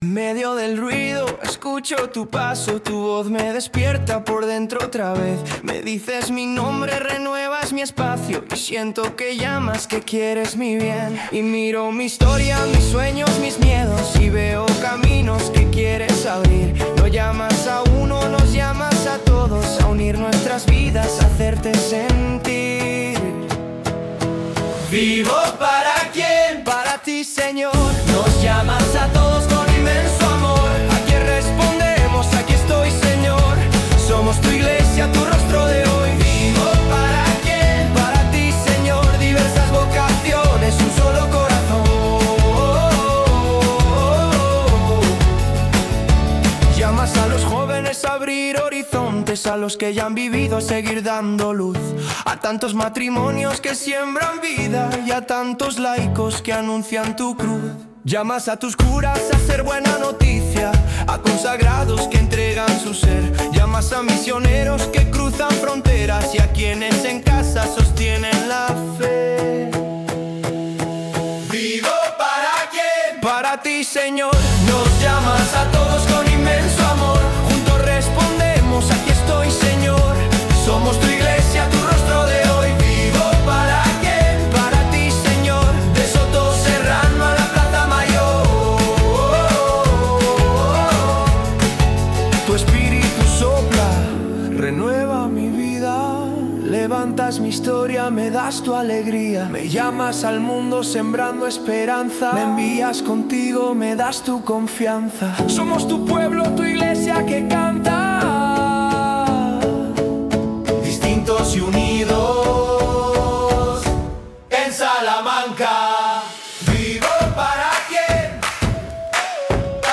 Himno-Congreso-Diocesano-de-Vocaciones.mp3